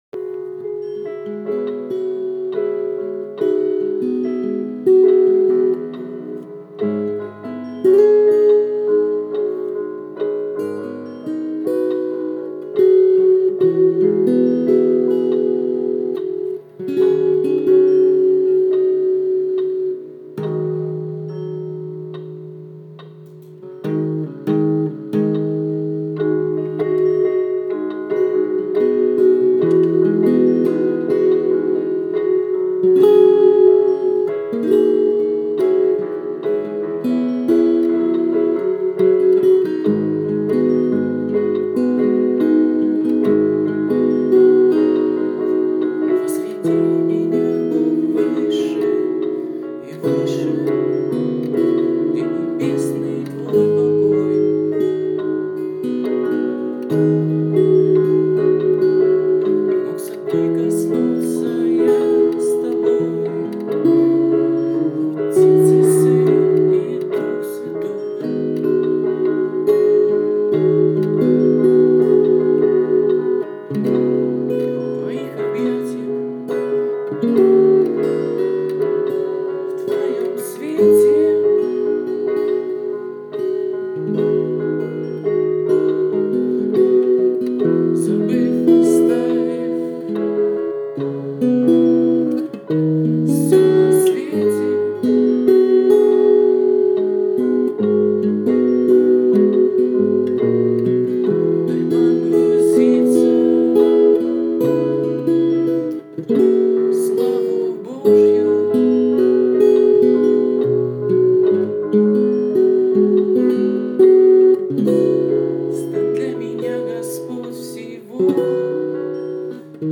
песня
Аb 76 ballad2
92 просмотра 164 прослушивания 1 скачиваний BPM: 76